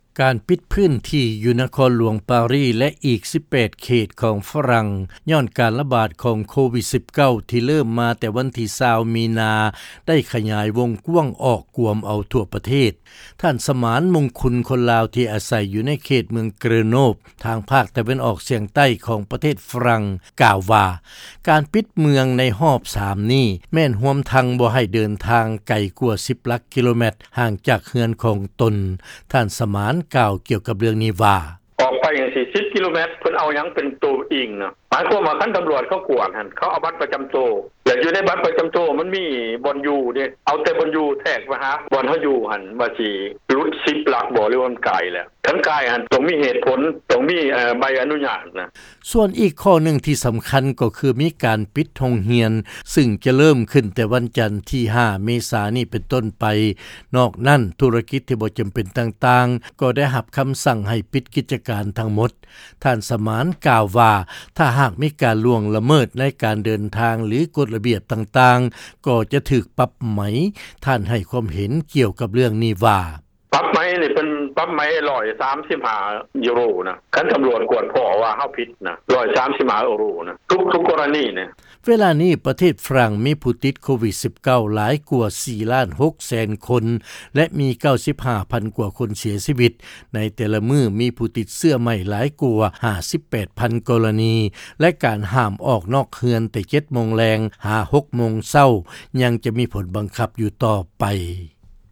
ເຊີນຟັງລາຍງານ ການປິດພື້ນທີ່ ຢູ່ປະເທດຝຣັ່ງ ຂະຫຍາຍວົງກ້ວາງ ກວມເອົາທົ່ວປະເທດ